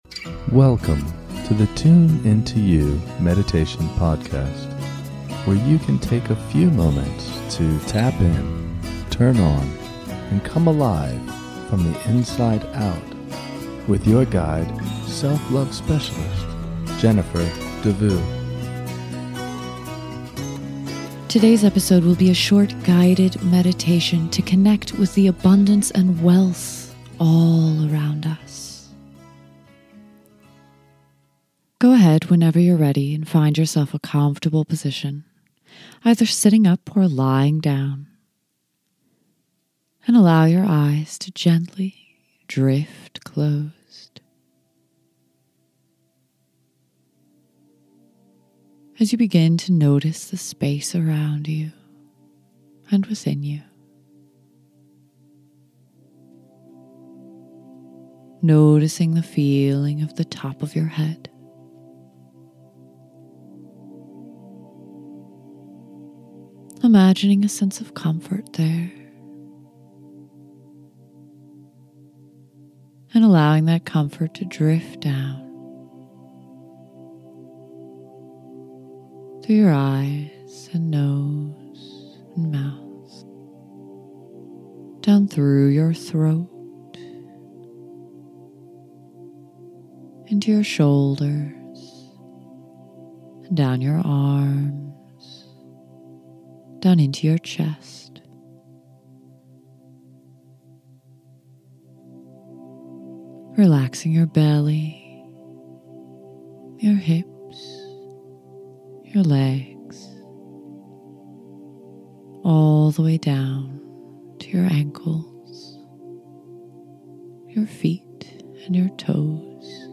This is a short guided meditation to connect you to the truth of abundance all around you.